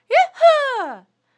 a_cheer1.wav